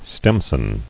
[stem·son]